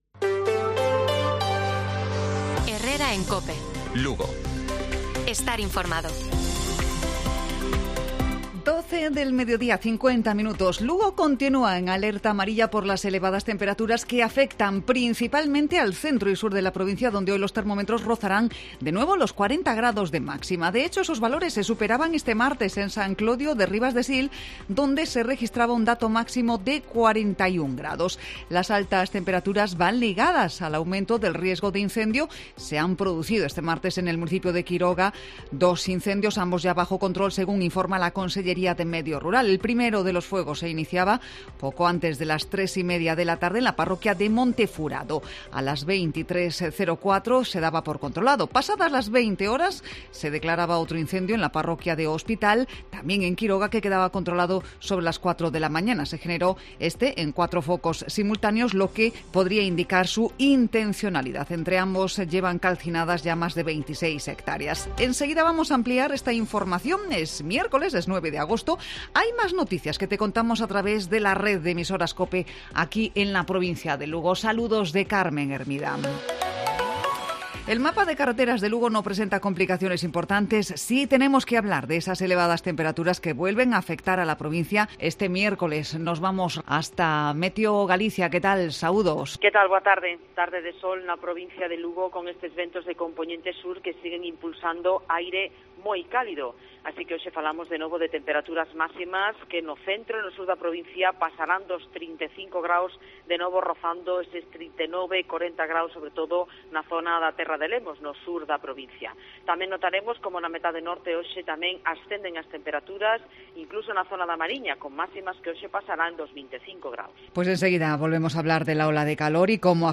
Informativo Provincial de Cope Lugo. Miércoles, 9 de agosto. 12:50 horas